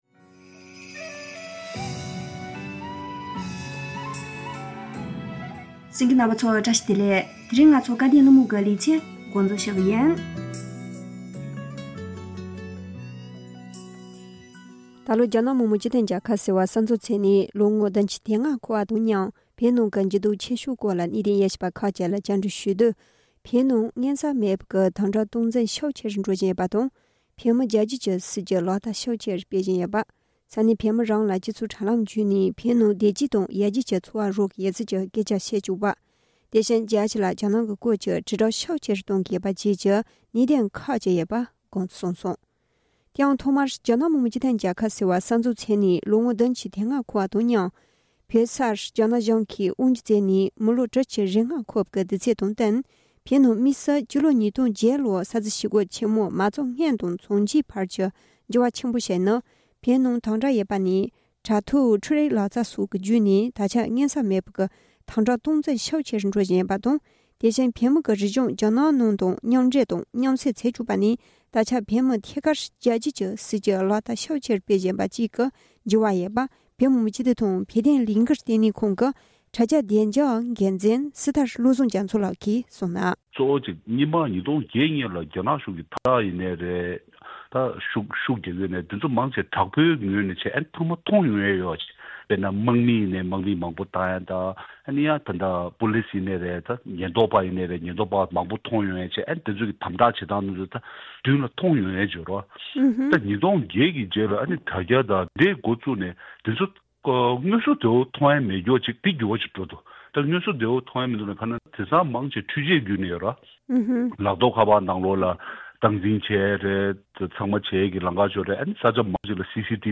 སྐབས་དོན་གླེང་མོལ་གྱི་ལེ་ཚན་ནང་།